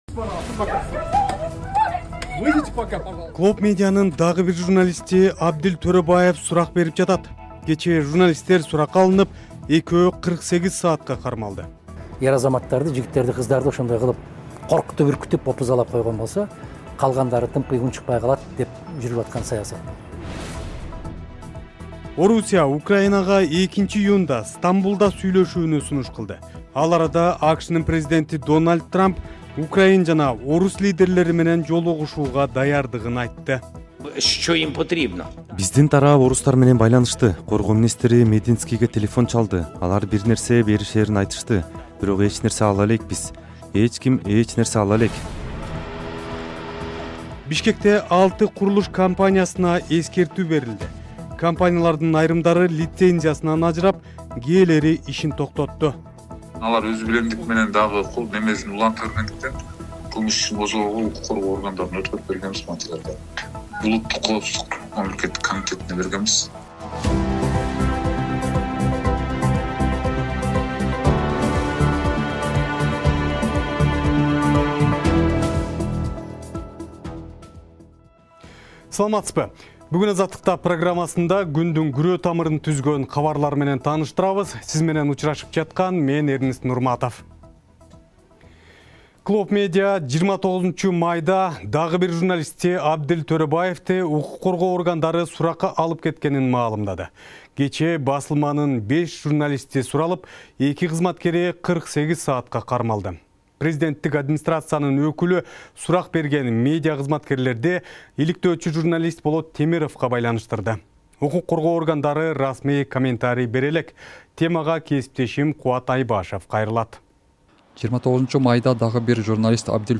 Жаңылыктар | 29.05.2025 | Клооп Медиа: Журналисттер суралып, экөө кармалды